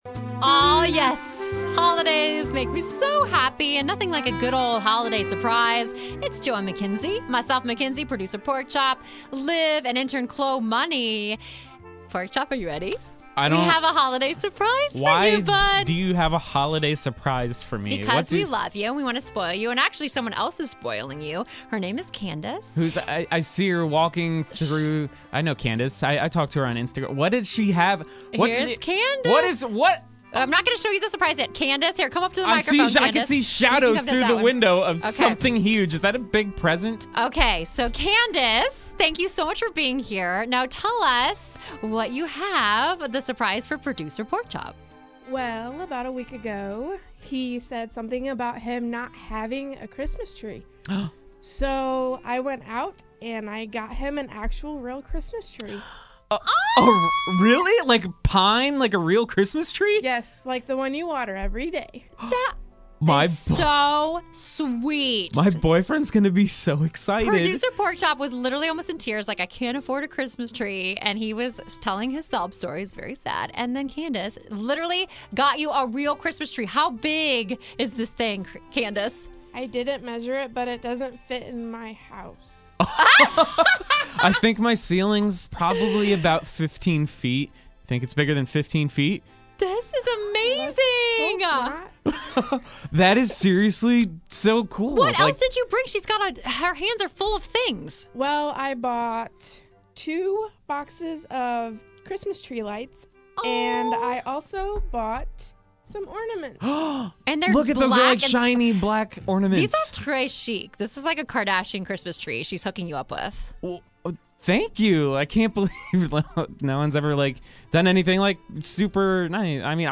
A listener came into studio with the BEST Christmas surprise!